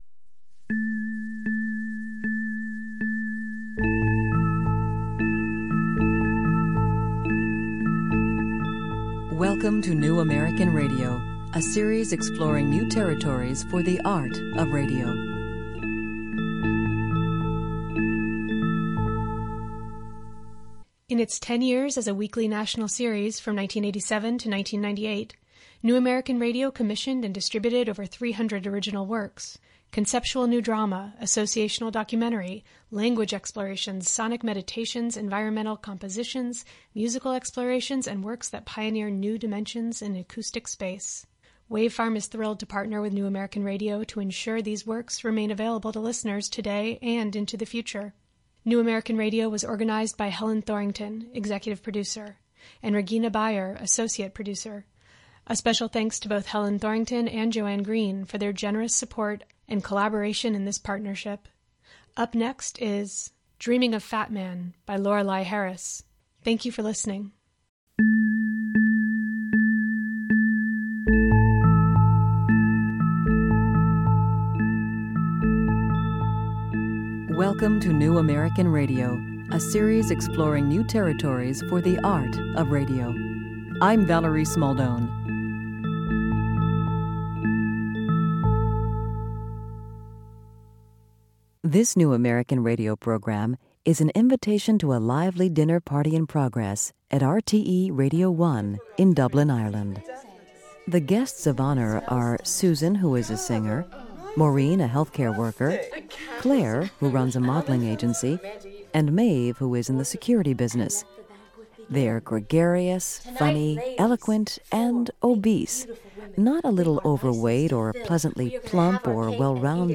A delightful and insightful dinner conversation among five women of size. Why do they love to eat?
In the work, the sounds from the animated dinner conversations blend with excerpts from one-to-one interviews with the women to shape lively portraits that defy easy categorizing.